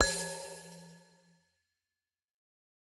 Minecraft Version Minecraft Version snapshot Latest Release | Latest Snapshot snapshot / assets / minecraft / sounds / entity / endereye / dead1.ogg Compare With Compare With Latest Release | Latest Snapshot